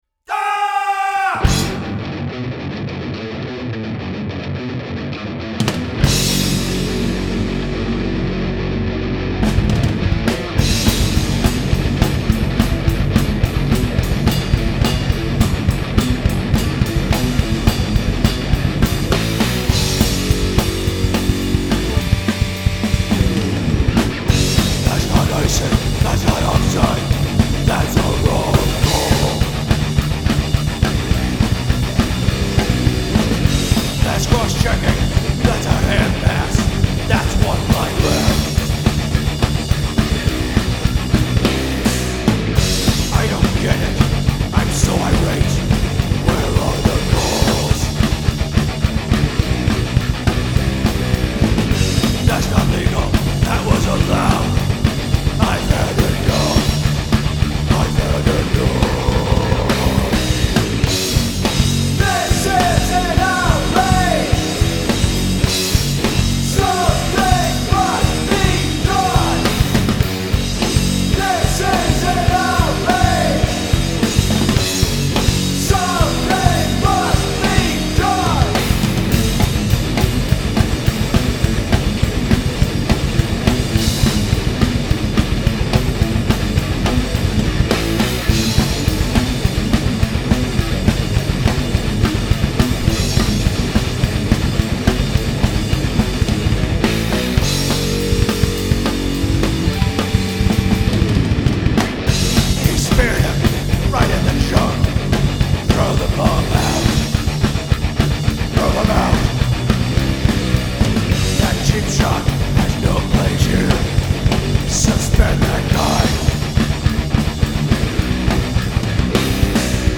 Musically, this one is driven by my love for both djent and speed metal. The song’s arc from “driving double kick” to “groove first, chops later” to “syncopated” really helped the lyrics out in this case. Also, the guitar tone changes here because this is when I started double-miking my amplifier.